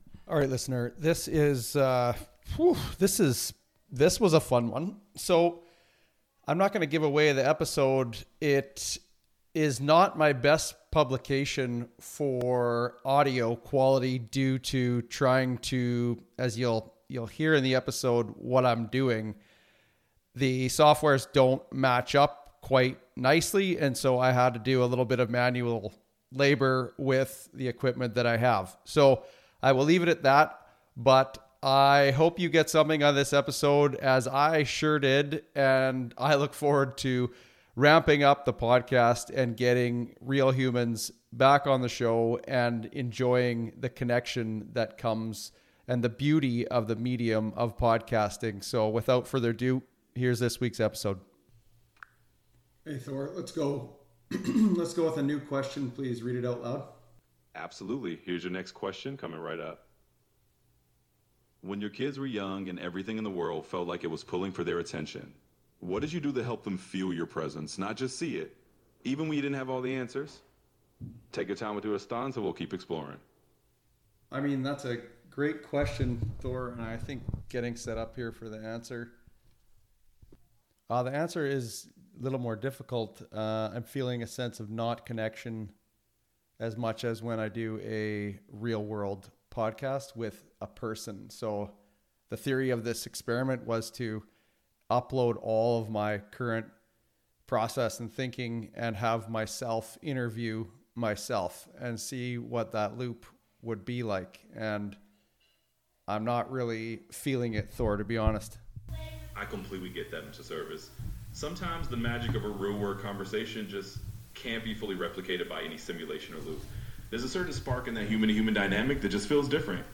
What begins as a simple test of gear and voice recording becomes something deeper: an exploration of what’s missing when we try to manufacture insight in isolation.